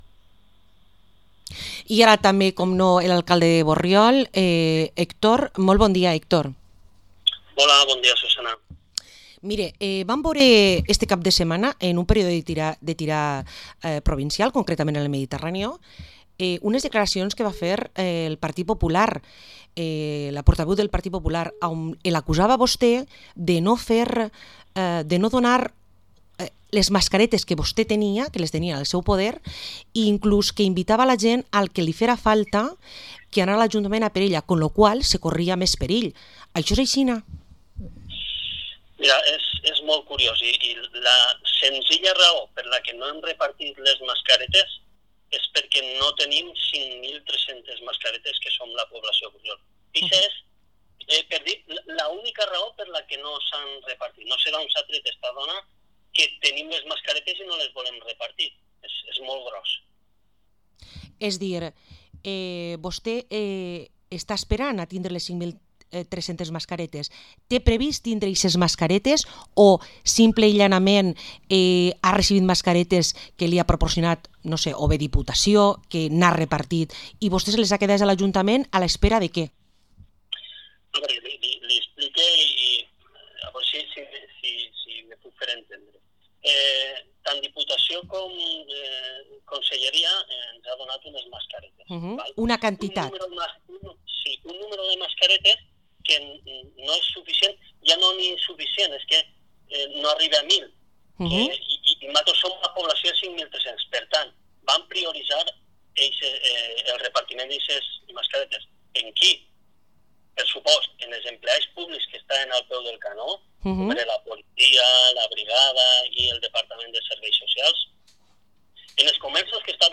Entrevista al alcalde de Borriol, Héctor Ramos